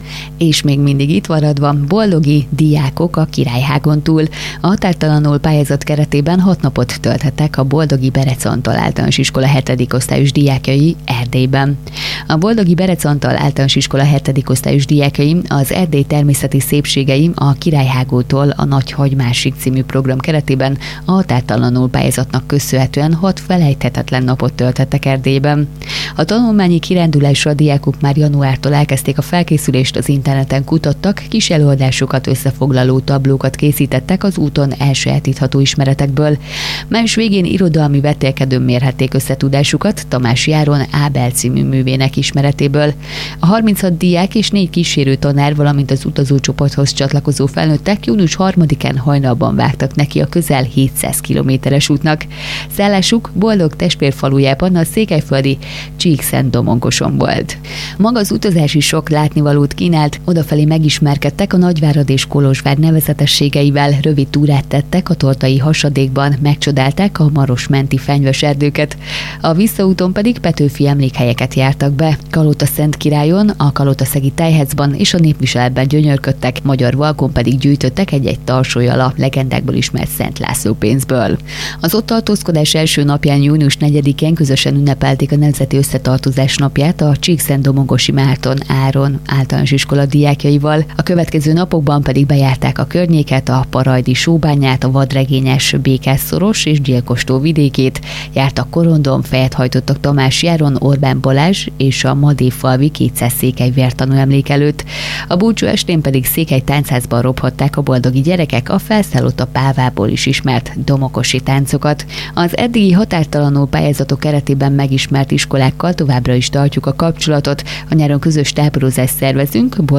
A pályázatról és az utazásról tudósítás és riport hangzott el a Dió Rádióban, a Hatvan Online oldalon pedig képes beszámoló jelent meg.
tudósítás: